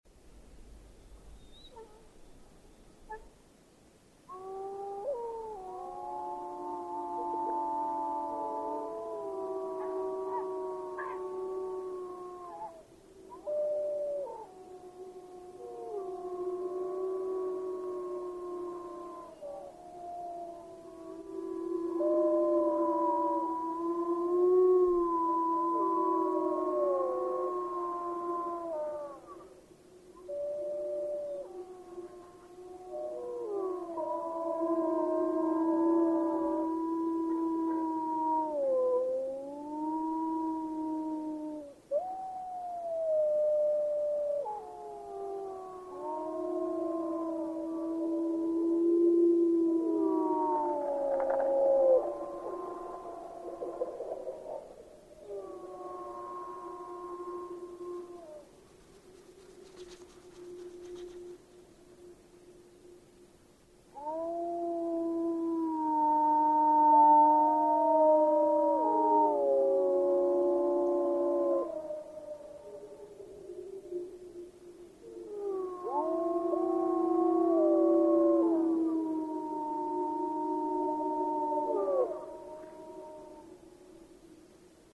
Wolves howling in Yellowstone Park
A pack of wolves howl during the evening while near an elk kill at Soda Butte. A Black-billed magpie flies by (1:00).
Recorded by National Parks Service.